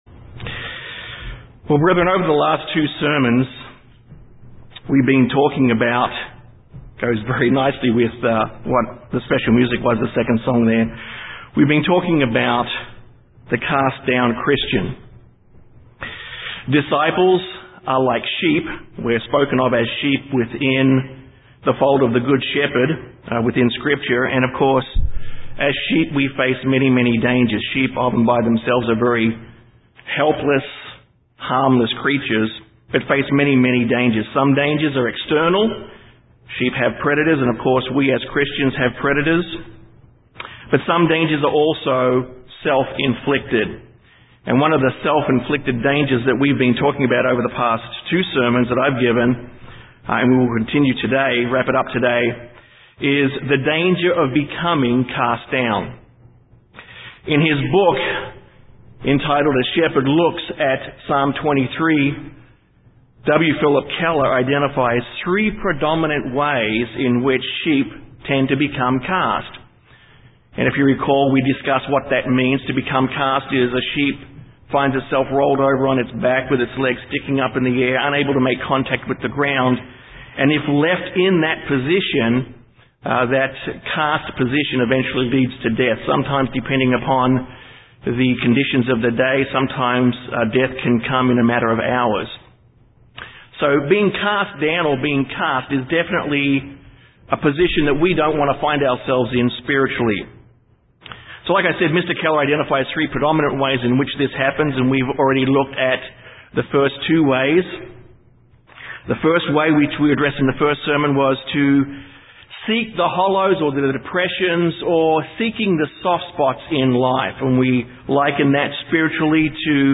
This sermon is the third of three addressing the concept of being "Cast Down". When a Christian becomes "Cast", their relationship with God is damaged and needs to be restored.